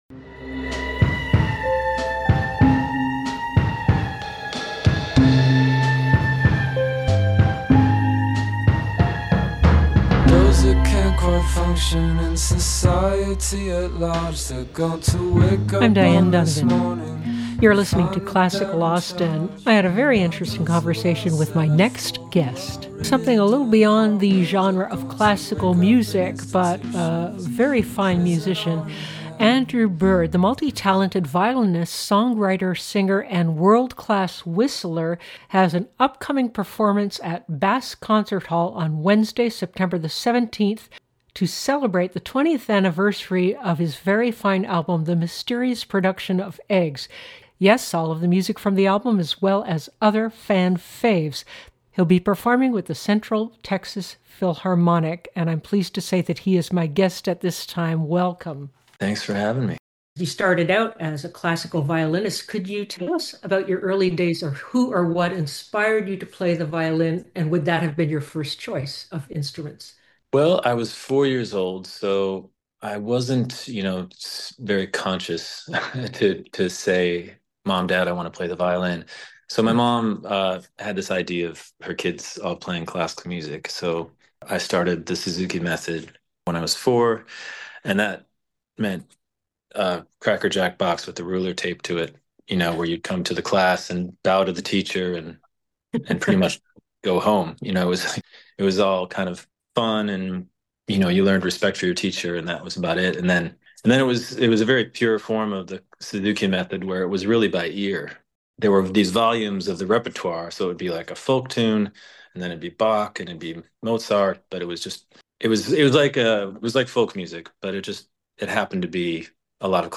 Andrew_Bird_Intv.mp3